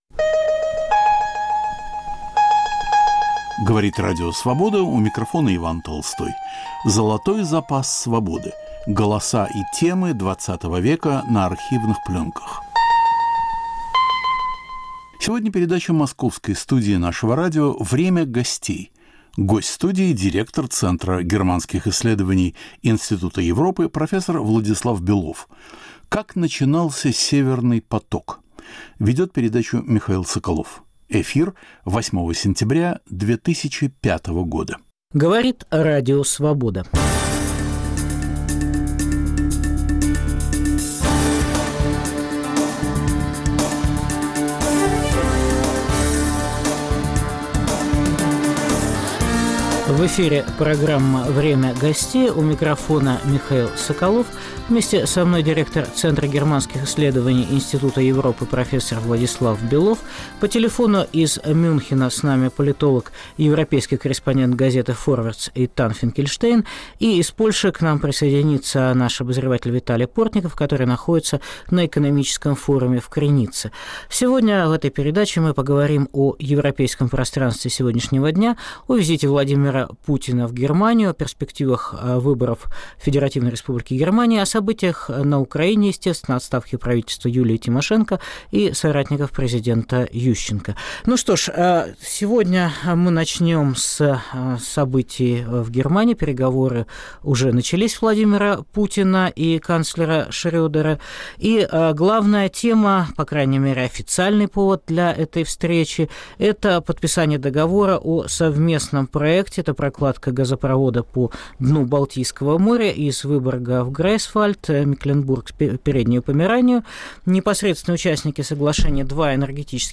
Как начинался Северный поток? Передача Московской студии